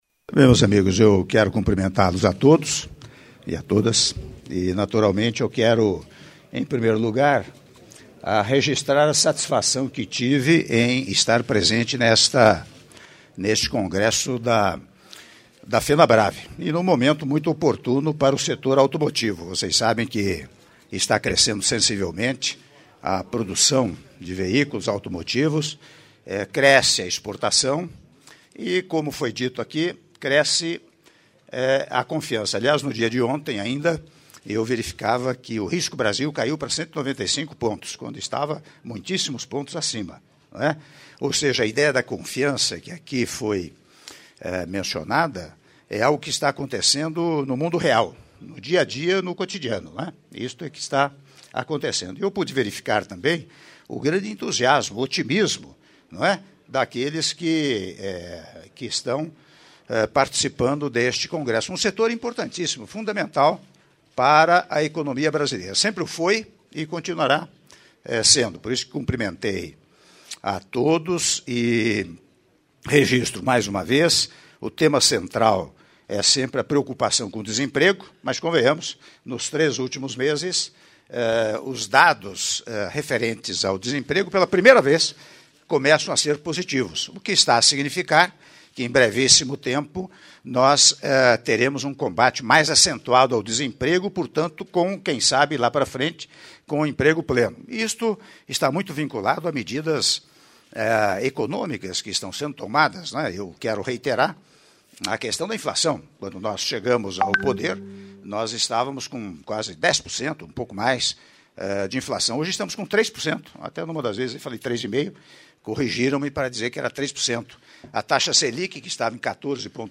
Áudio da entrevista coletiva concedida pelo Presidente da República, Michel Temer, após cerimônia de abertura do 27º Congresso & ExpoFenabrave - (04min27s) - São Paulo/SP